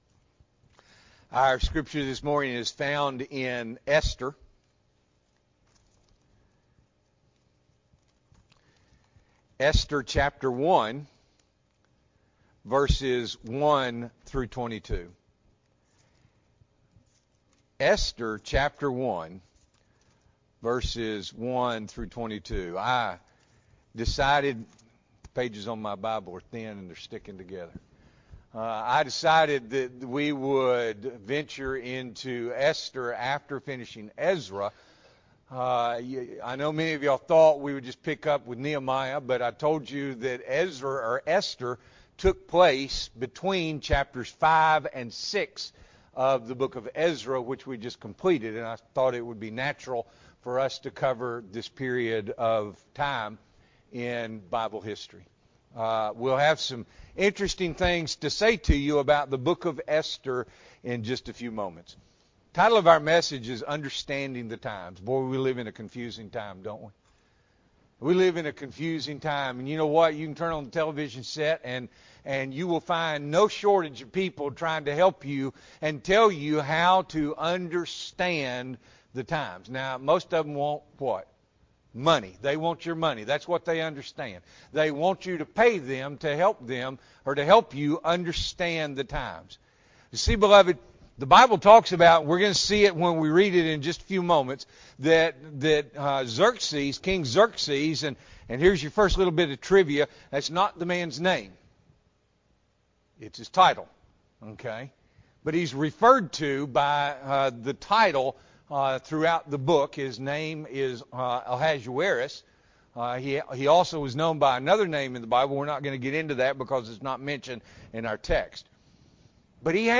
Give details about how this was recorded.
October 15, 2023 – Morning Worship